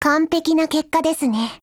贡献 ） 协议：Copyright，其他分类： 分类:少女前线:史蒂文斯520 、 分类:语音 您不可以覆盖此文件。